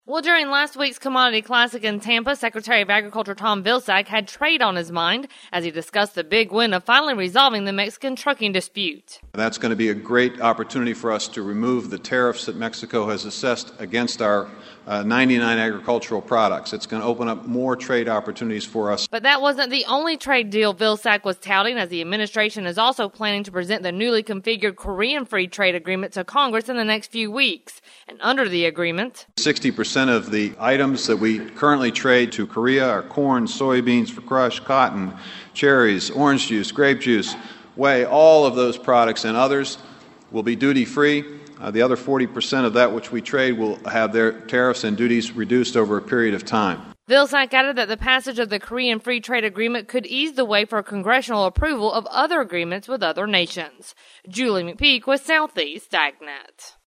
During last weeks Commodity Classic, Secretary of Agriculture, Tom Vilsack, discussed both the Mexican Trucking Dispute and the Korean Free Trade Agreement.